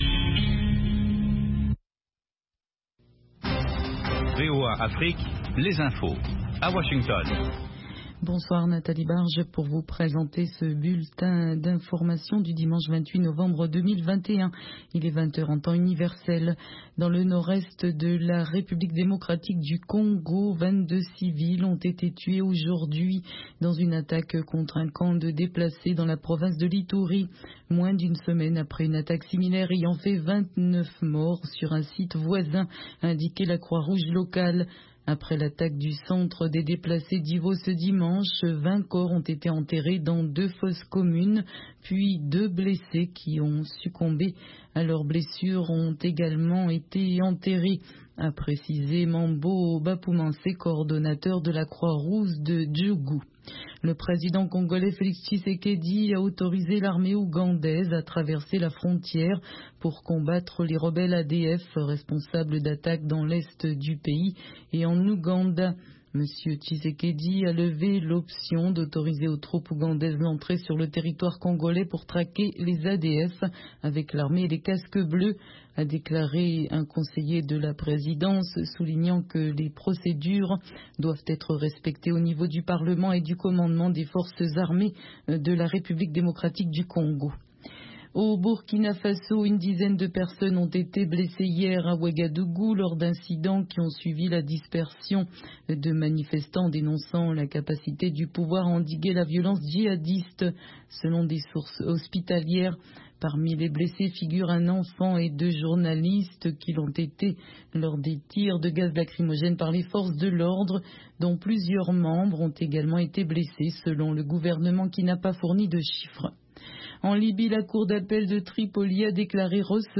RM Show - French du blues au jazz
Blues and Jazz Program Contactez nous sur facebook